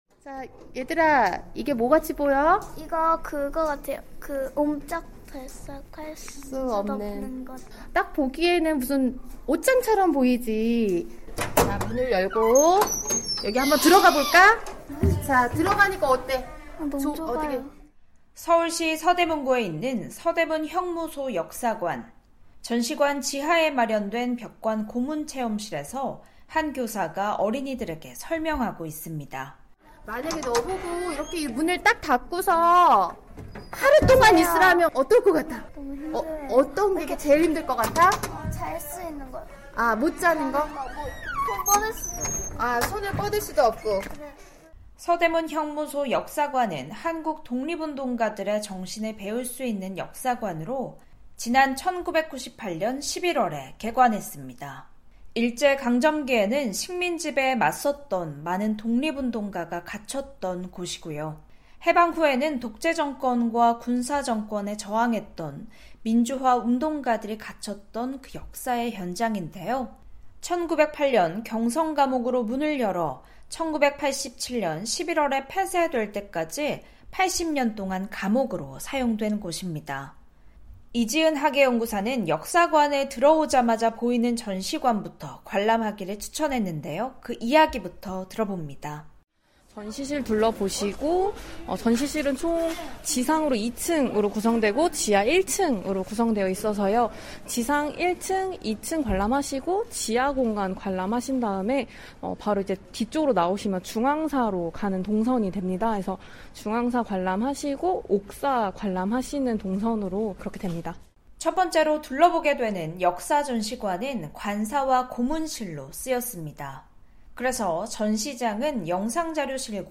변화하는 한국의 모습을 살펴보는 '헬로서울', 오늘은 한국의 광복절을 맞아 한민족 독립을 향한 투쟁의 역사가 남아 있는 '서대문형무소역사관' 현장으로 안내해드립니다.